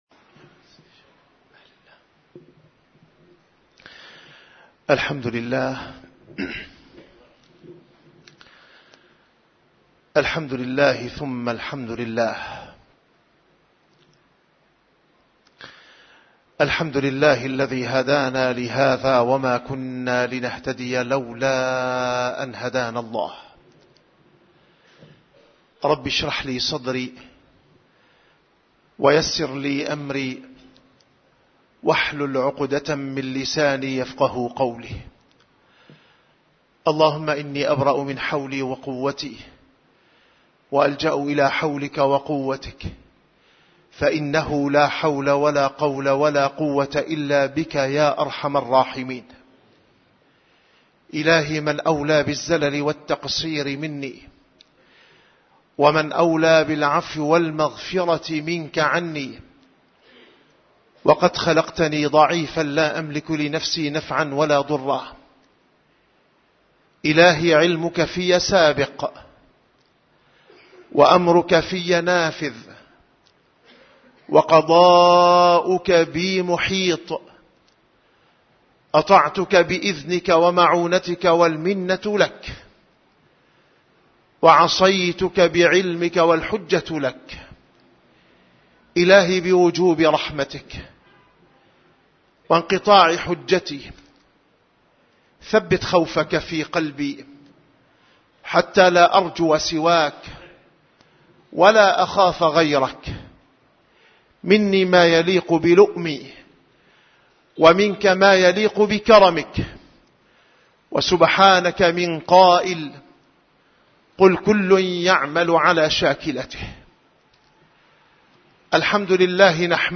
- الخطب - عزّة المؤمن وركائزها